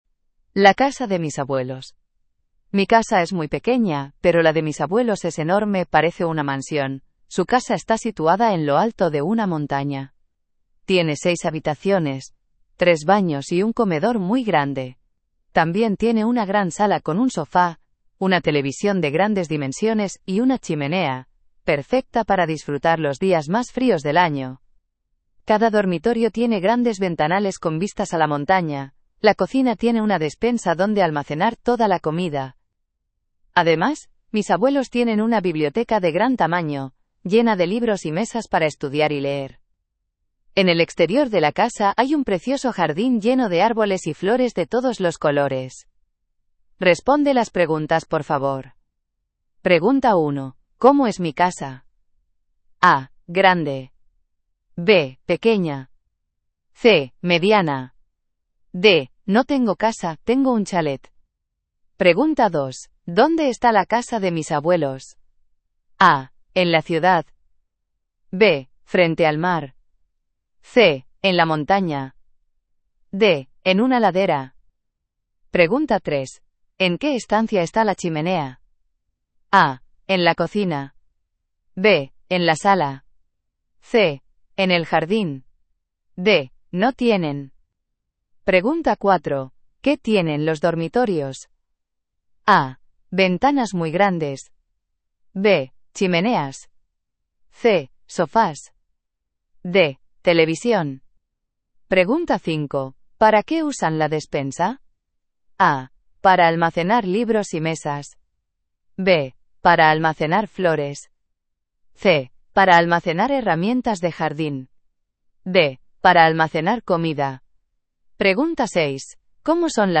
Spanien